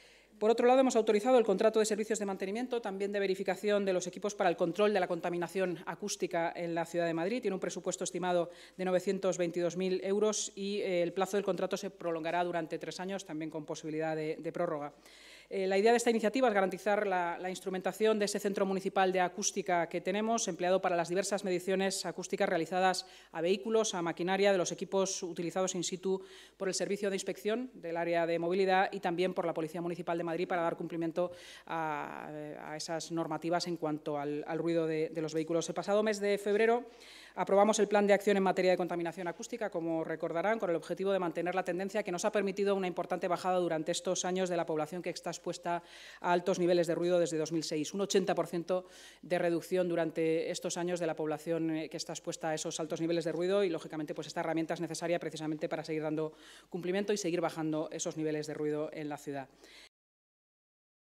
Con un presupuesto estimado de 922.000 euros, el plazo del contrato se prolongará durante tres años (con fecha de arranque prevista en el mes de septiembre), con la opción de prorrogarlo dos años más, como ha destacado la vicealcaldesa y portavoz municipal, Inma Sanz, en rueda de prensa.